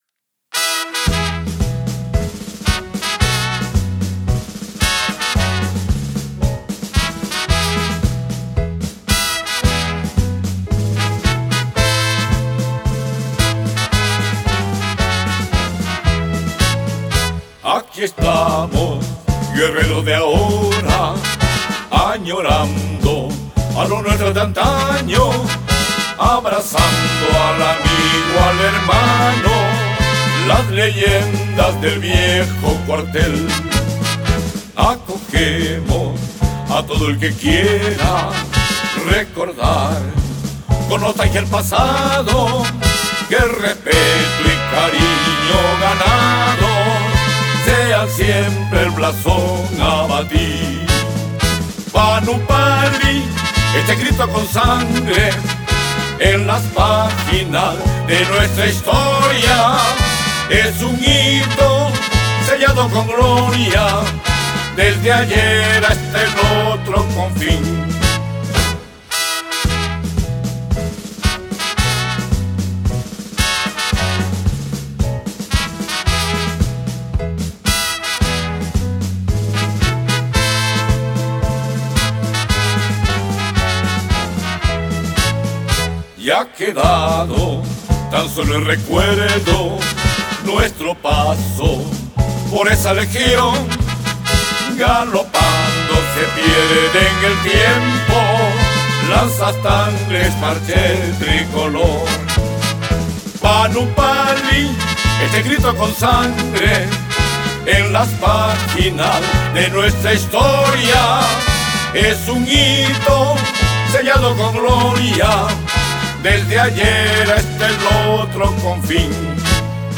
7.1 HIMNO DEL GRUPO HONORÍFICO PANUPALI (CANTADO)
himno-del-ghp-41-cantado-ok-1.mp3